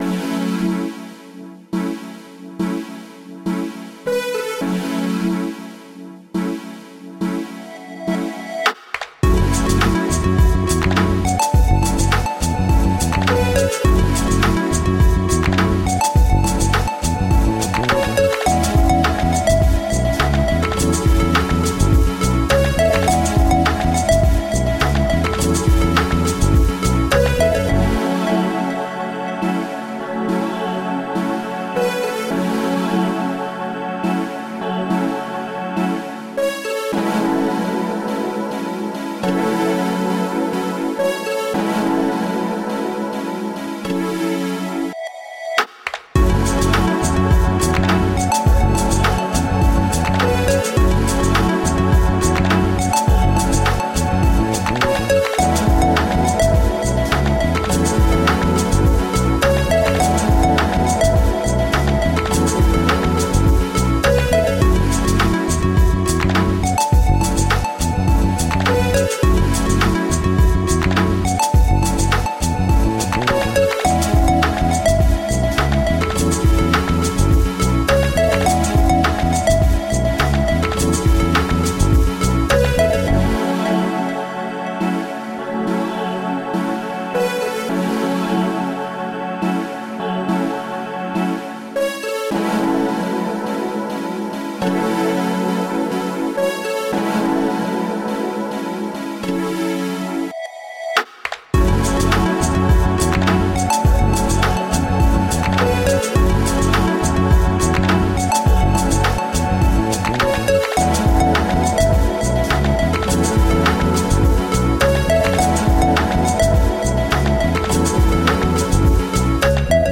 The most uplifting electronic music.
Uplifting, energizing, melodic, and emotionally-hitting.